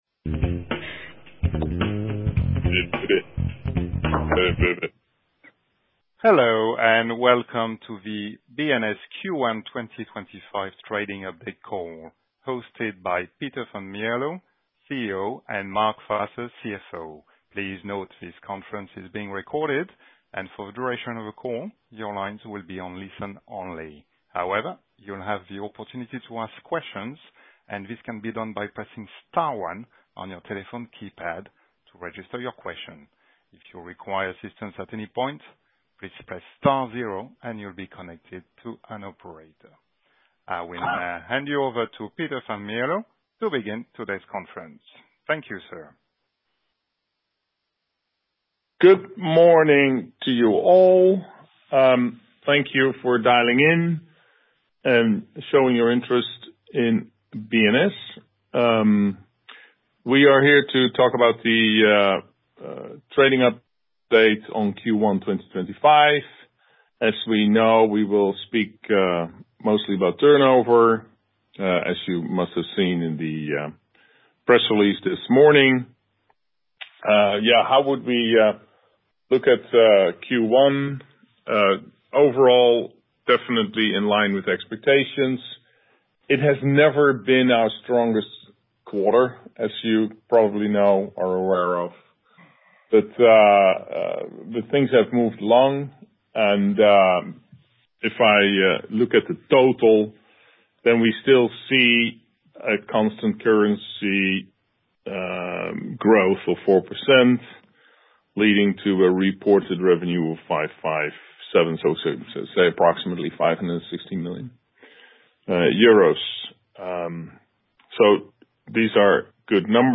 A replay of this call is available on this page.